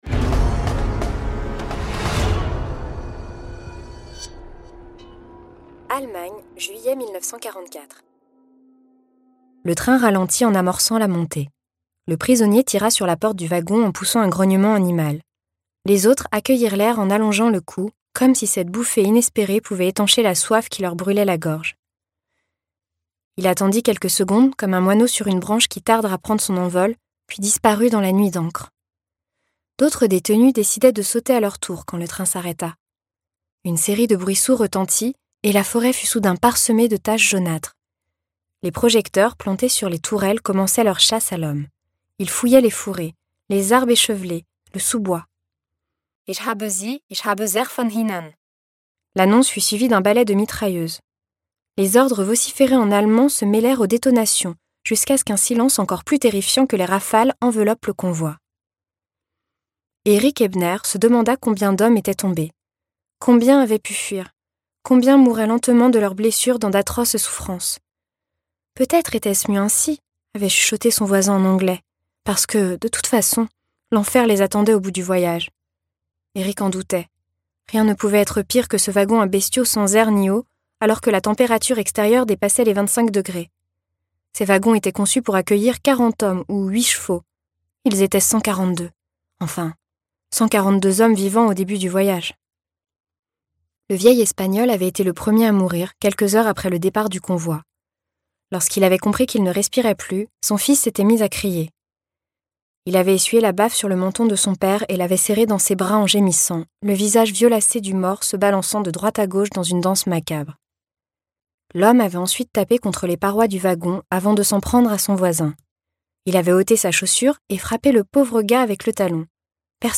» - Télé Loisirs Ce livre audio est interprété par une voix humaine, dans le respect des engagements d'Hardigan.